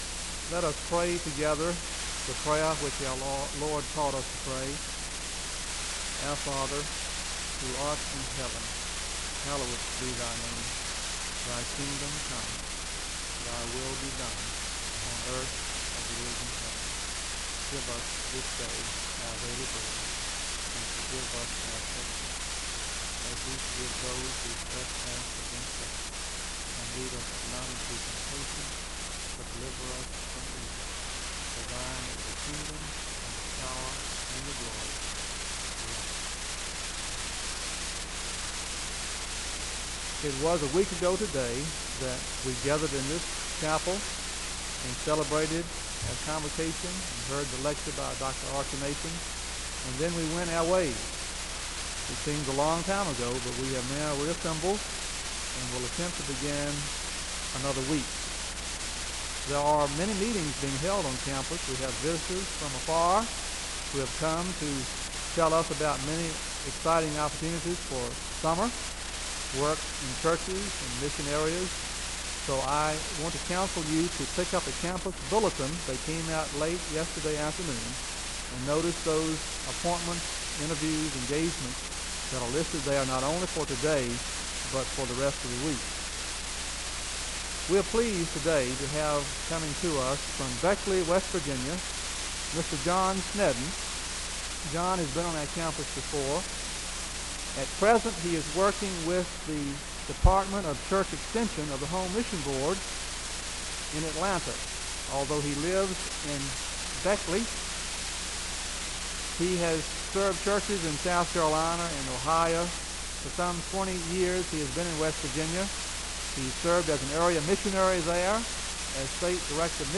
Audio quality is poor.
The service begins with the saying of the Lord’s Prayer (00:00-00:38).